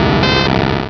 sovereignx/sound/direct_sound_samples/cries/magnemite.aif at master